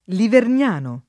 [ livern’ # no ]